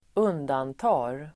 Uttal: [²'un:danta:r]